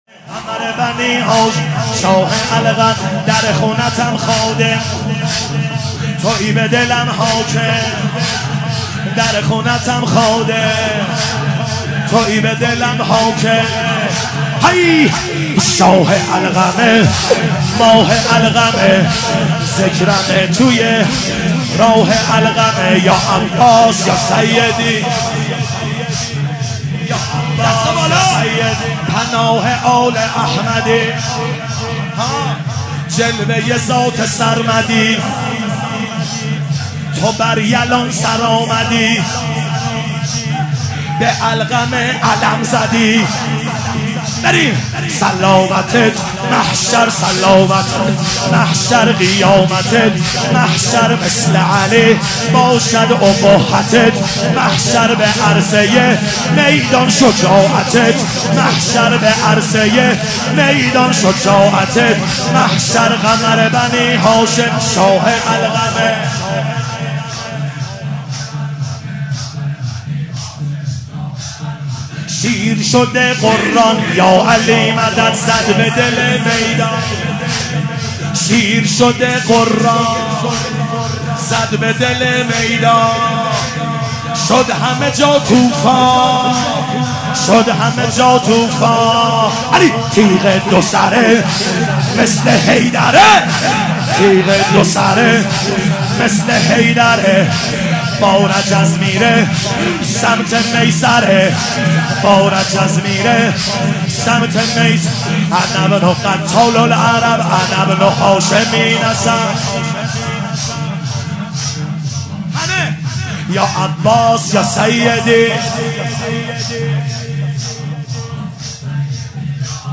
مداحی
محرم الحرام 1440 هیئت جنت العباس(ع) کاشان